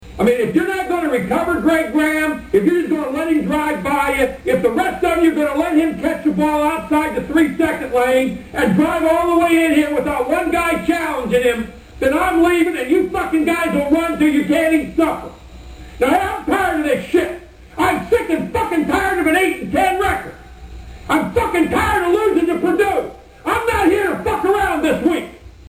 Bobby Knight Explicit halftime speech